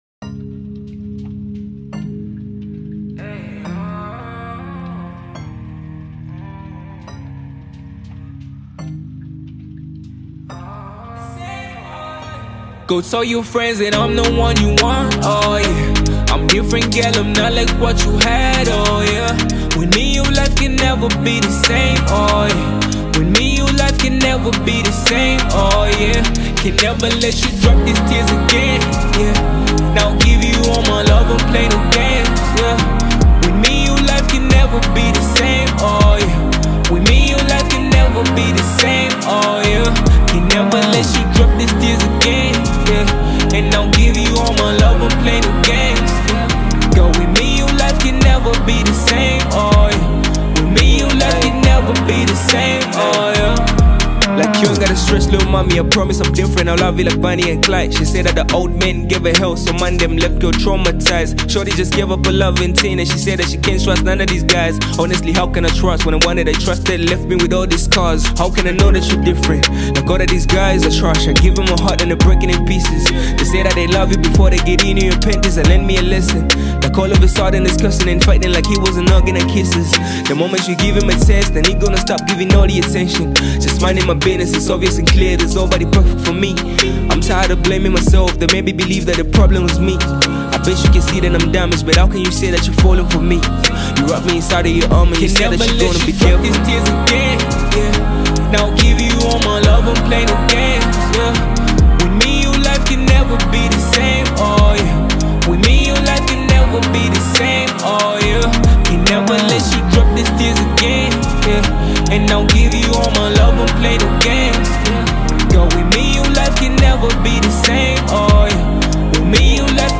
This soulful song
emotive vocals